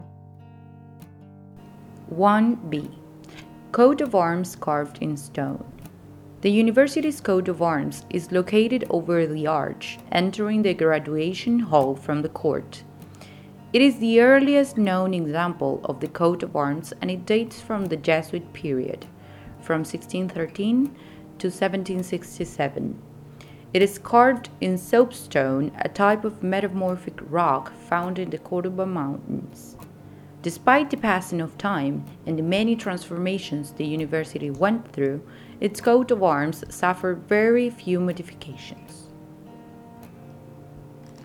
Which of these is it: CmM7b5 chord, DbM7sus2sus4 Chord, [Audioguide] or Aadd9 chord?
[Audioguide]